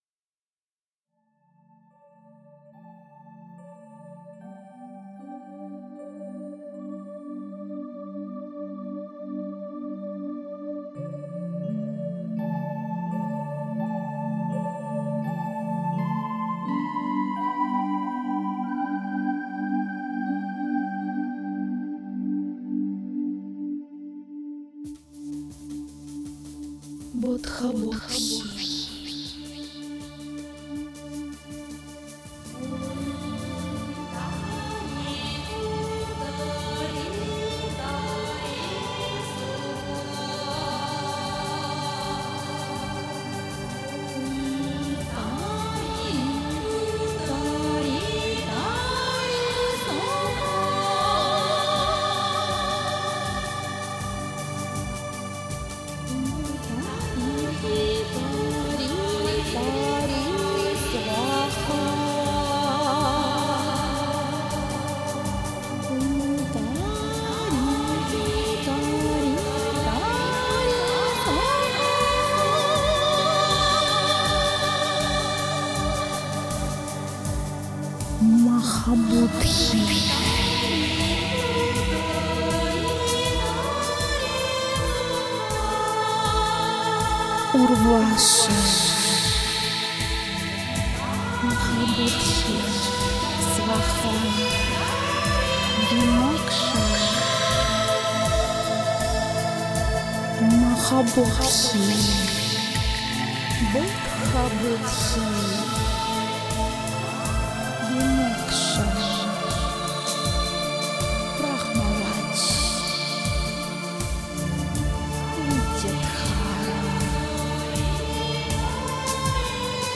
Духовная музыка Мистическая музыка Медитативная музыка